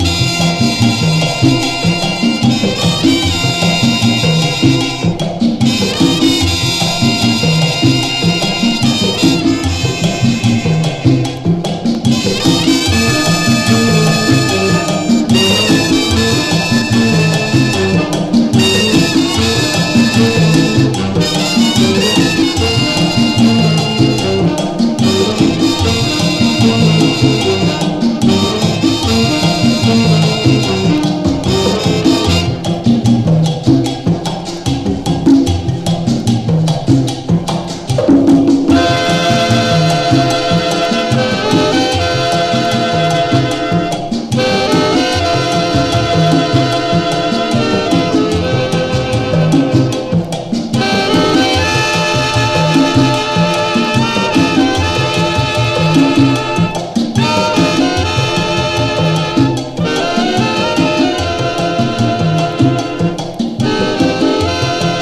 WORLD / LATIN / PACHANGA / CHARANGA / CHA CHA CHA / MAMBO
全曲楽しい61年チャランガ～パチャンガ・グルーヴ！